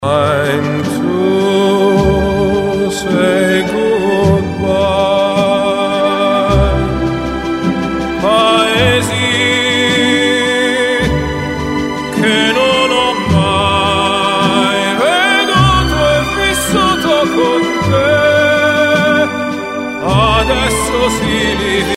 tenor
pop music